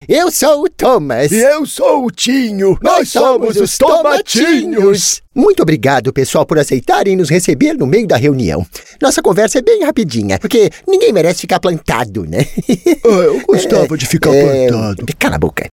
Naturelle, Amicale, Fiable, Corporative, Accessible